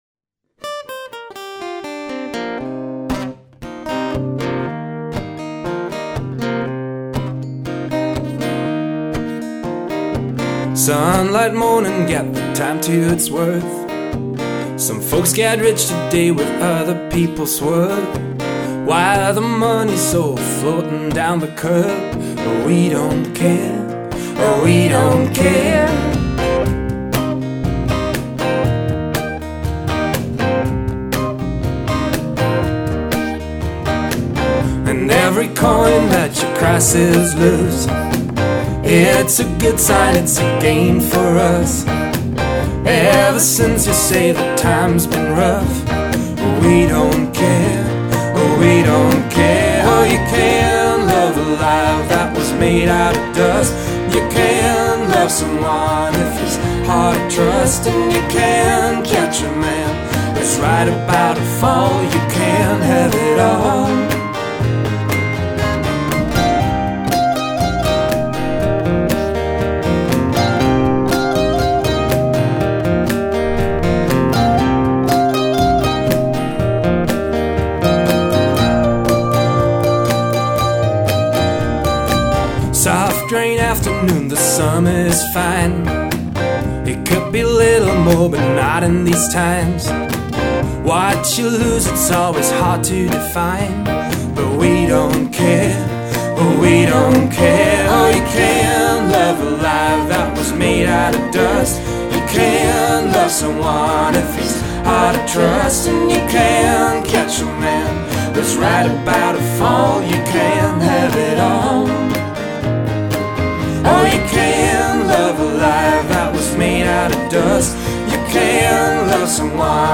They sing in English and play American folk.